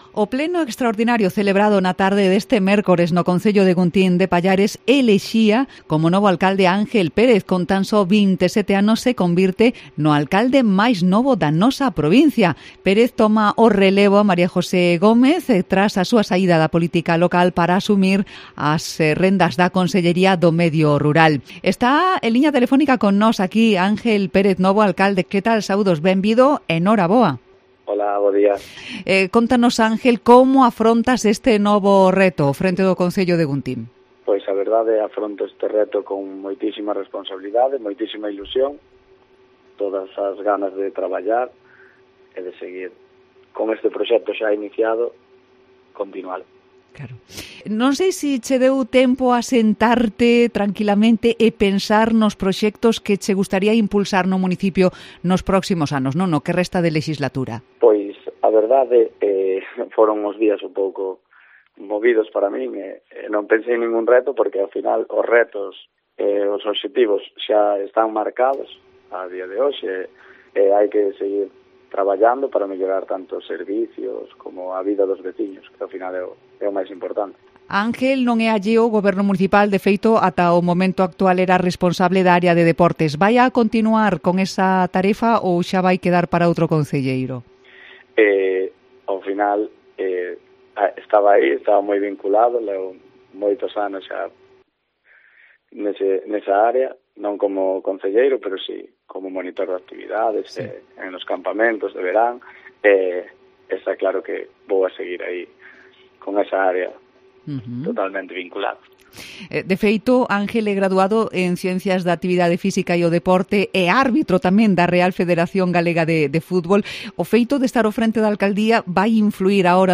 El alcalde más joven de la provincia concede su primera entrevista a COPE Lugo